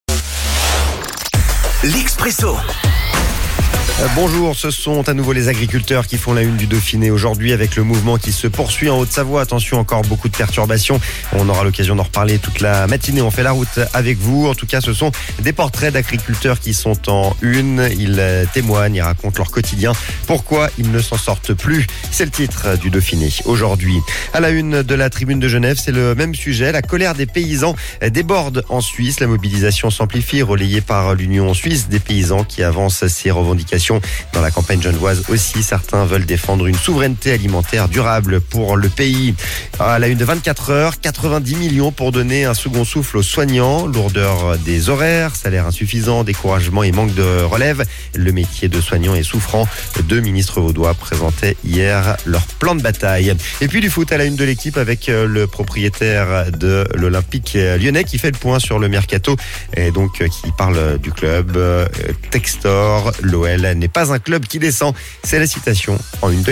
La revue de presse